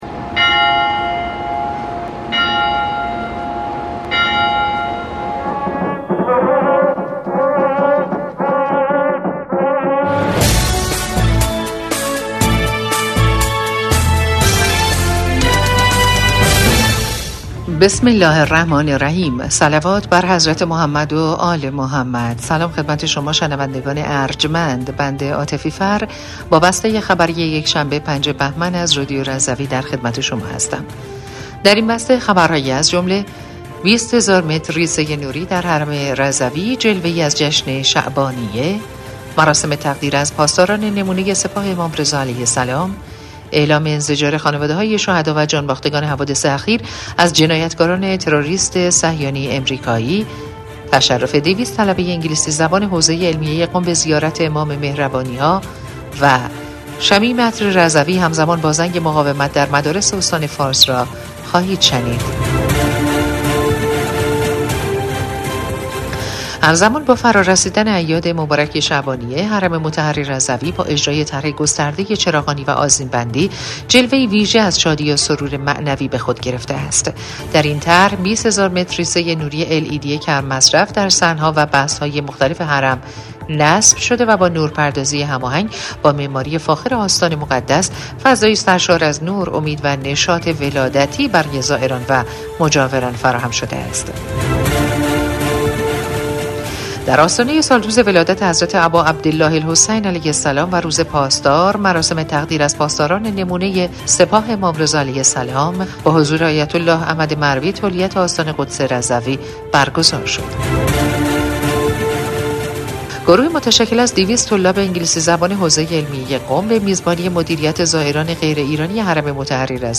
بسته خبری پنجم بهمن ۱۴۰۴ رادیو رضوی؛